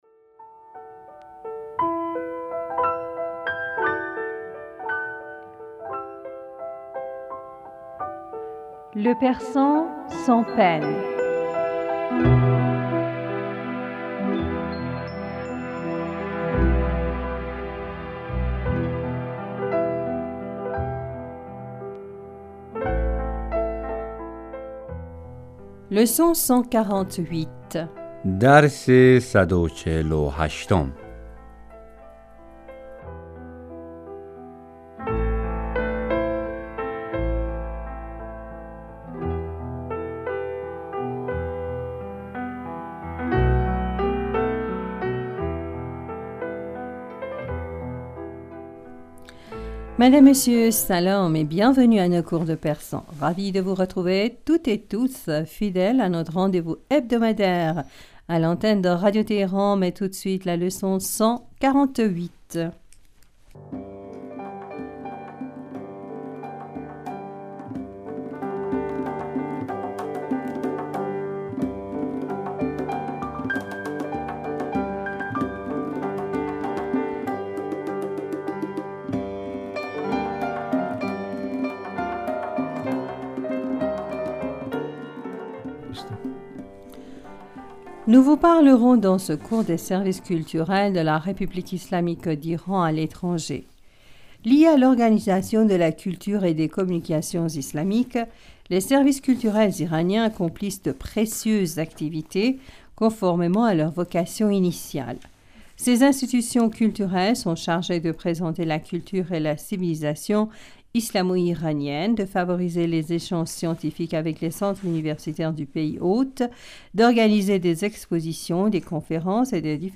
Ecoutez et répétez après nous.